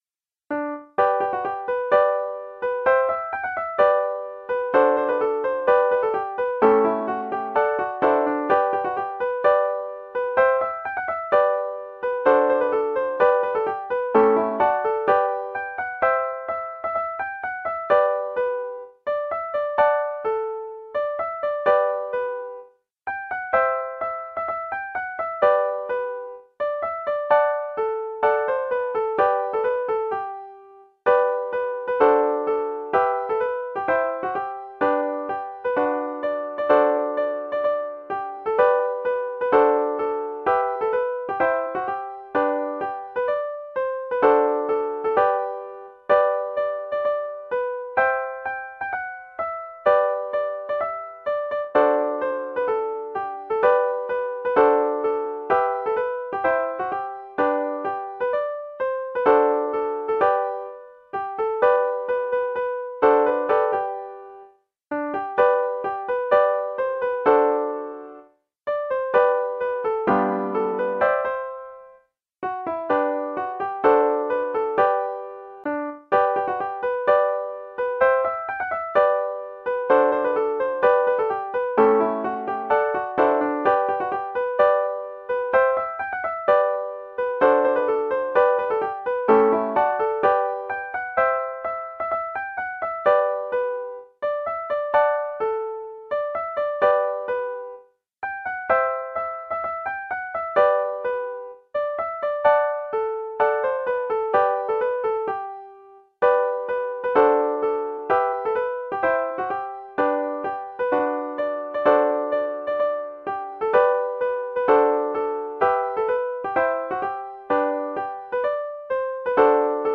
Redwing set Resources: The Score of the music (PDF) Listen to the tune (speed 120 beats per minute) (MP3) Listen to the tune (speed 70 beats per minute) (MP3) List by Title List by Composer List by State
RedwingWaltzMaRRValley120.mp3